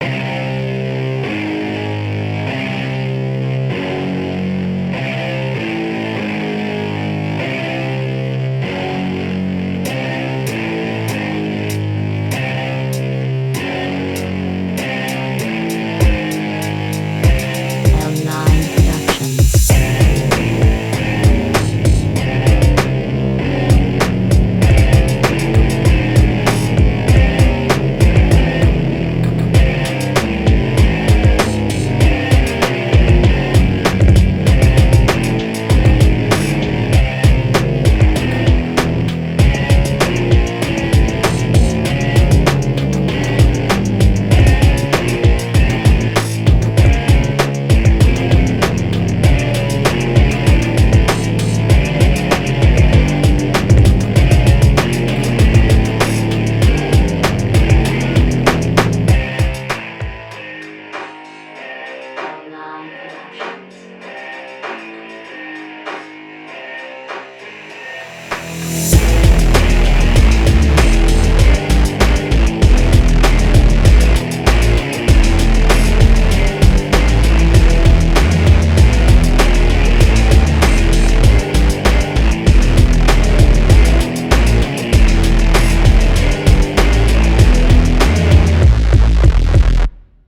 Text ist naja nicht so brisant und der Flow auch vorsichtig formuliert ausbaufähig, es wirkt …
Keine Runde, dafür ein nicer Beat den ich in meiner nächsten Runde definitiv nicht nehmen …